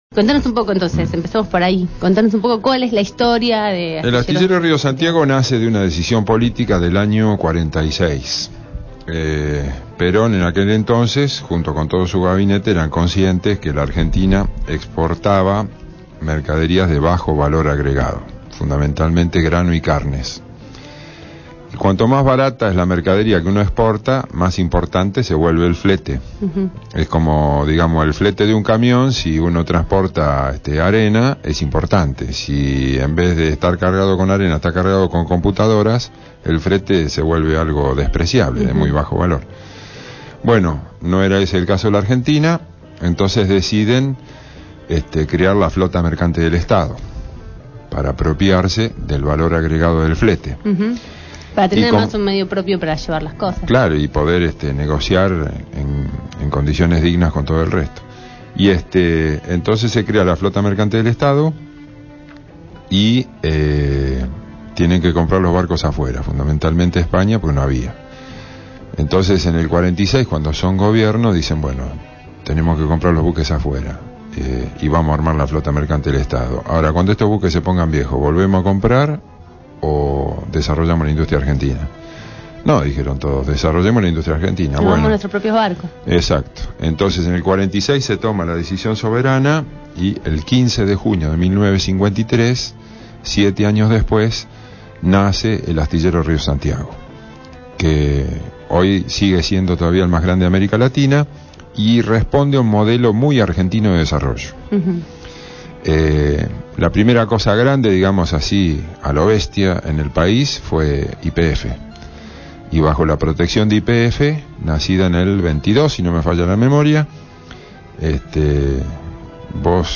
En abramos la Boca: Entrevista
En el estudio de Radio Grafica estubo compartiendo la tarde